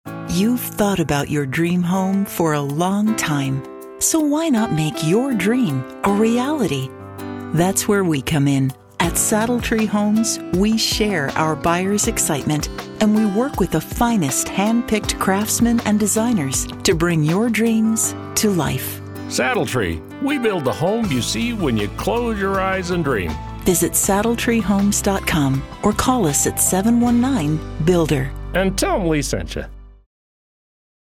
Radio Ad 1